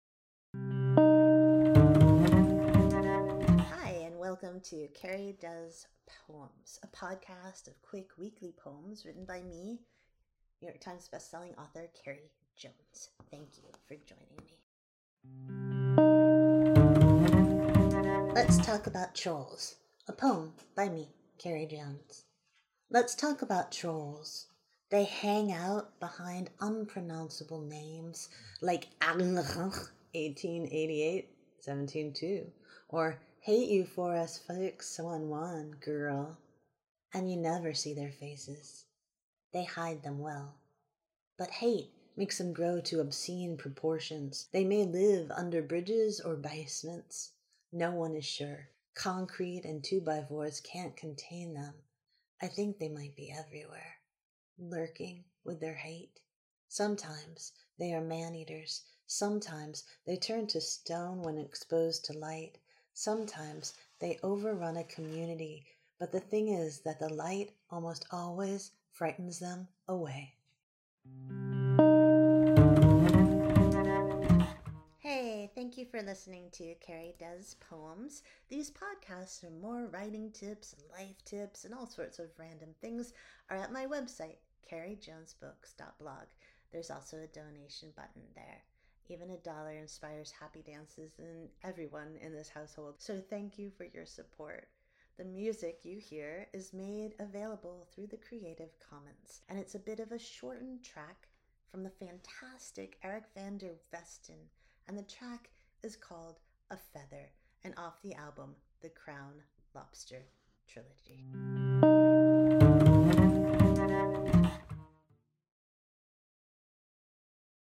A poem
The music you hear is made available through the creative commons and it’s a bit of a shortened track from the fantastic Eric Van der Westen and the track is called "A Feather" and off the album The Crown Lobster Trilogy.